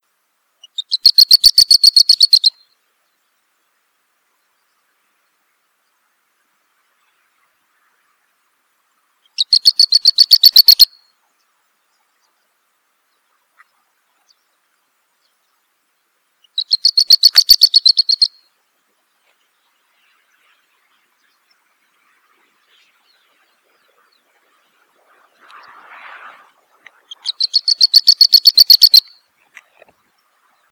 Upucerthia dumetaria - Bandurrita
Voz aguda que casi siempre emite al estar posada en pequeños arbustos.
Repite fit fit al caminar.
bandurrita.wav